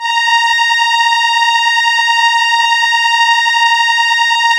Index of /90_sSampleCDs/Keyboards of The 60's and 70's - CD1/STR_Elka Strings/STR_Elka Violins
STR_ElkaVlsA#6.wav